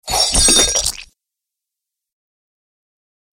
دانلود آهنگ دعوا 28 از افکت صوتی انسان و موجودات زنده
جلوه های صوتی
دانلود صدای دعوا 28 از ساعد نیوز با لینک مستقیم و کیفیت بالا